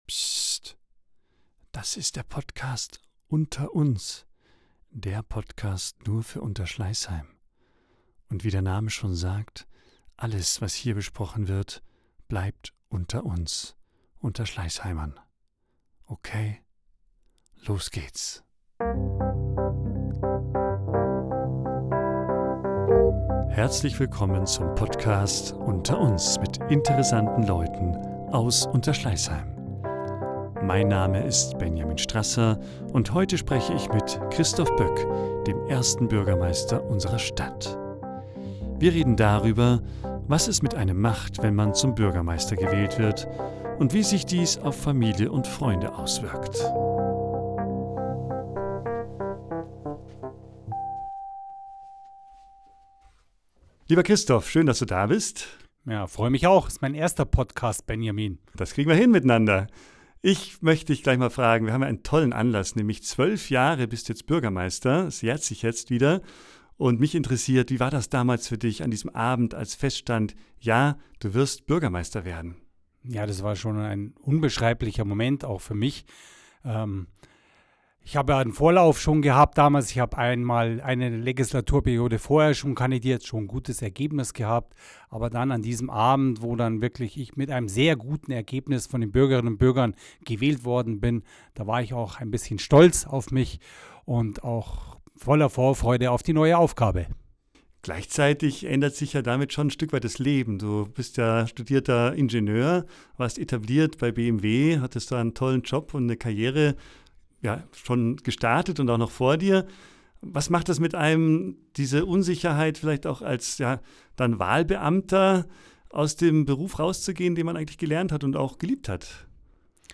spricht mit dem Ersten Bürgermeister der Stadt Unterschleißheim anlässlich seines 12-jährigen Dienstjubiläums darüber, was es mit einem macht, wenn man wirklich Bürgermeister wird und was es mit Familie und Freunden macht.